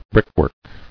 [brick·work]